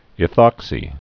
(ĭ-thŏksē) also eth·ox·yl (ĭ-thŏksəl)